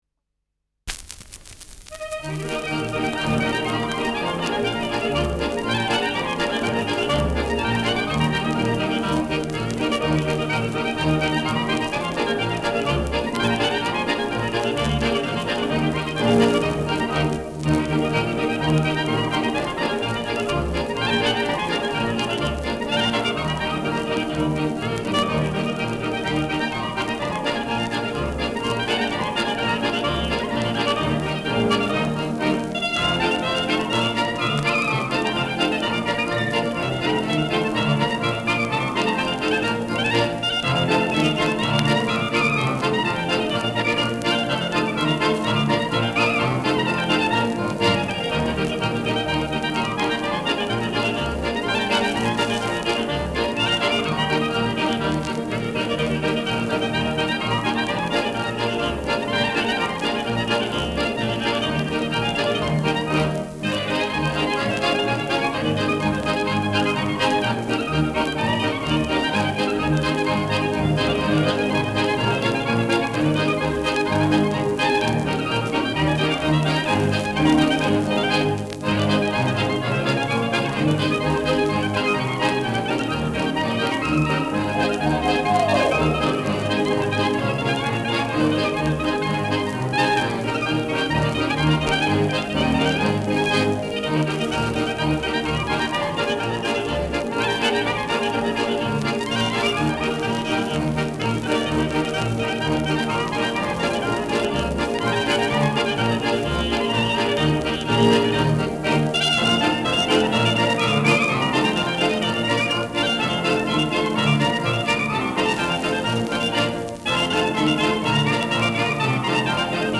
Schellackplatte
leichtes Knistern
[Zürich] (Aufnahmeort)
Ländlerkapelle* FVS-00018